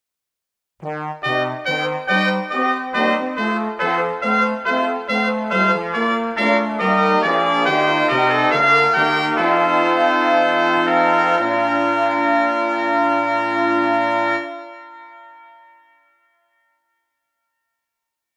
音源は、比較のために、全て金管にしています。